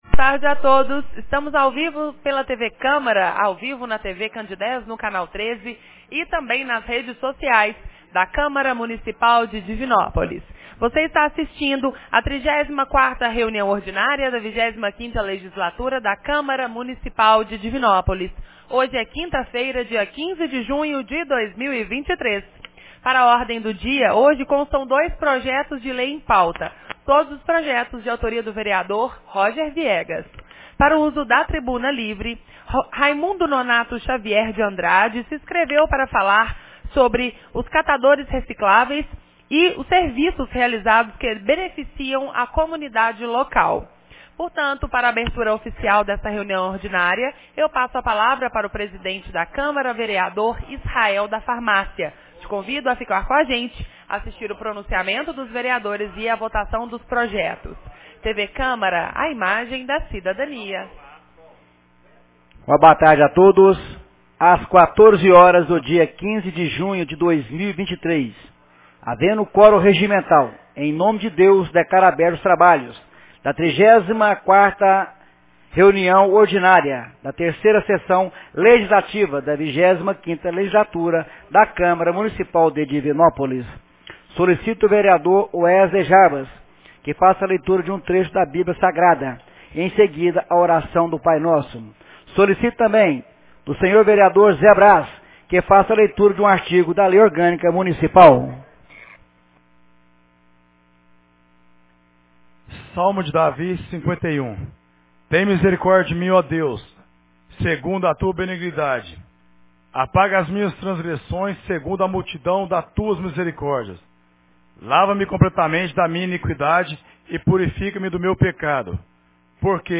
34ª Reunião Ordinária 15 de junho de 2023